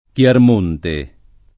vai all'elenco alfabetico delle voci ingrandisci il carattere 100% rimpicciolisci il carattere stampa invia tramite posta elettronica codividi su Facebook Chiarmonte [ k L arm 1 nte ] top. — forma it. ant. per Clermont (-Ferrand)